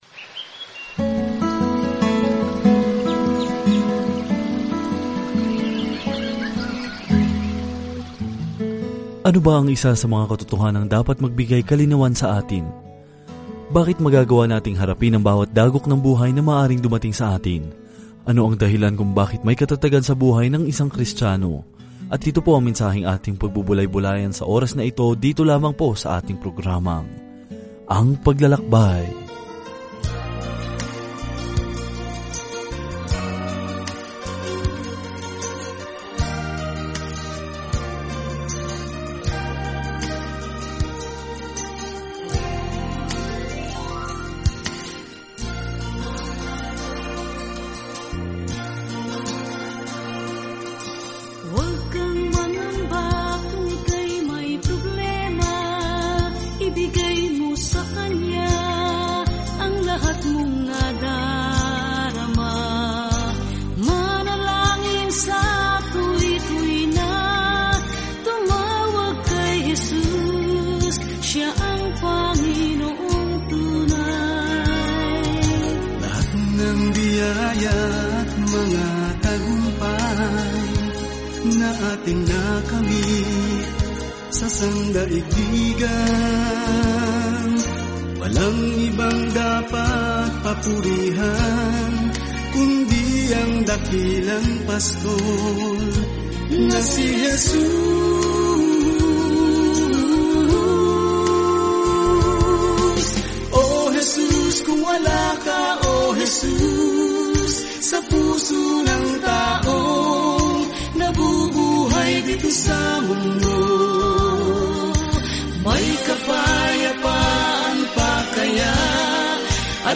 Araw-araw na paglalakbay sa 1 John habang nakikinig ka sa audio study at nagbabasa ng mga piling talata mula sa salita ng Diyos.